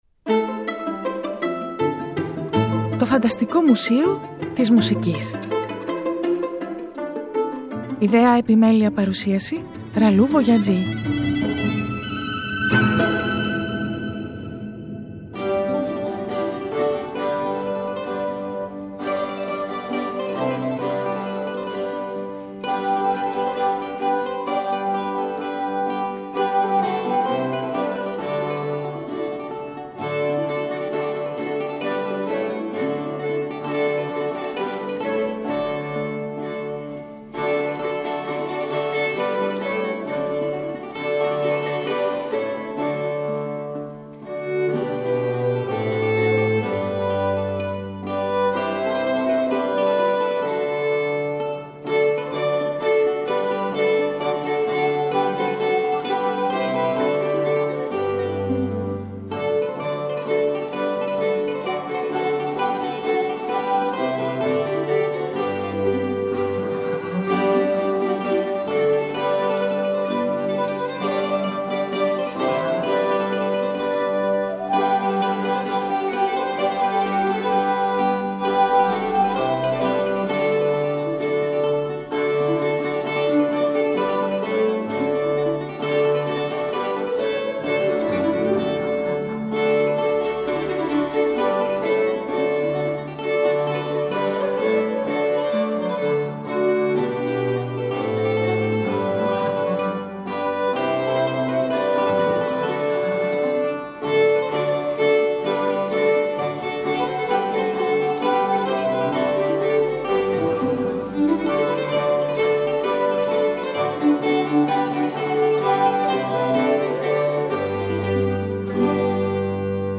Το 2024 Το ΦΑΝΤΑΣΤΙΚΟ ΜΟΥΣΕΙΟ της ΜΟΥΣΙΚΗΣ (Τhe FANTASTIC MUSEUM of MUSIC) είναι μια ραδιοφωνική εκπομπή , ένας «τόπος» φαντασίας στην πραγματικότητα .
Κι εμείς με ιστορίες, σκέψεις και πολλή μουσική περιηγούμαστε στις αίθουσες του με ελεύθερες επιλογές ή θεματικές ξεναγήσεις .